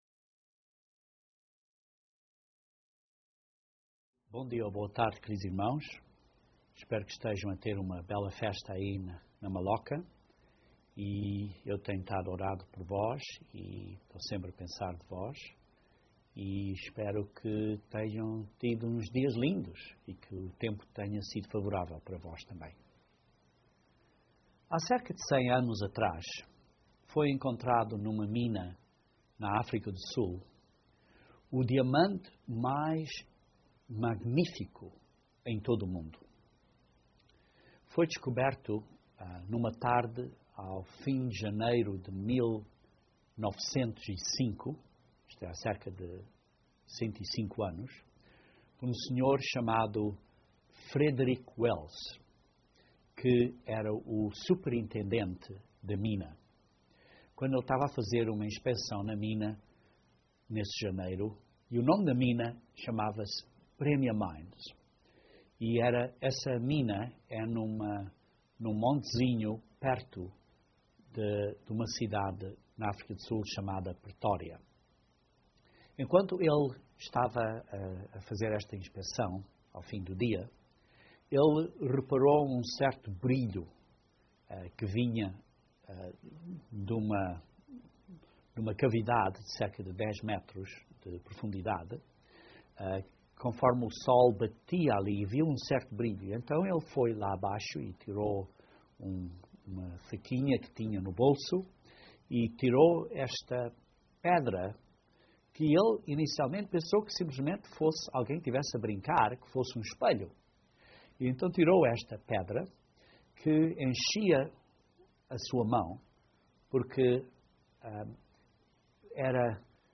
Este sermão gravado para membros durante a Festa de Tabernáculos, descreve alguns passos no processo do tratamento de pedras preciosas e demonstra uma analogia com a vida Cristã.